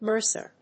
音節mer・cer 発音記号・読み方
/mˈɚːsɚ(米国英語), mˈəːsə(英国英語)/